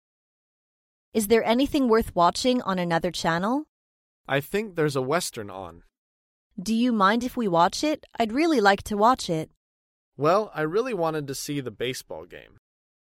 在线英语听力室高频英语口语对话 第439期:不同的电视节目的听力文件下载,《高频英语口语对话》栏目包含了日常生活中经常使用的英语情景对话，是学习英语口语，能够帮助英语爱好者在听英语对话的过程中，积累英语口语习语知识，提高英语听说水平，并通过栏目中的中英文字幕和音频MP3文件，提高英语语感。